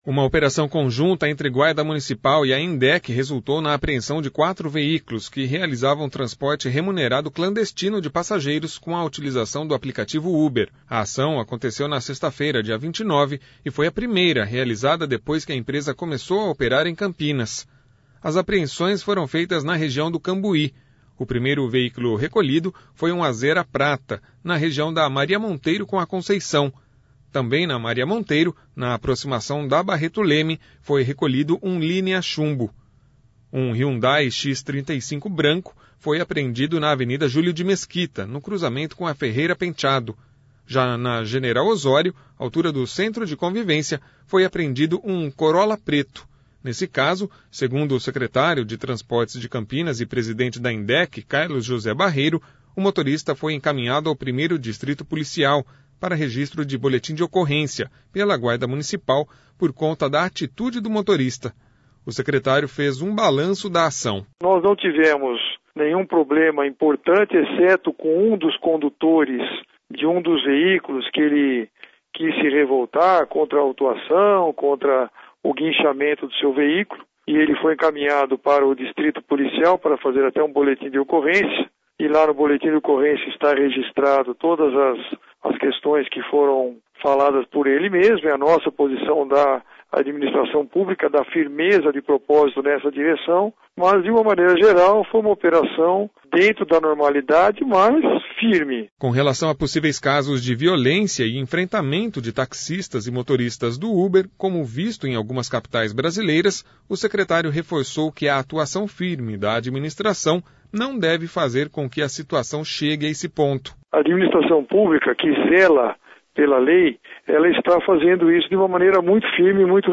O secretário fez um balanço da ação.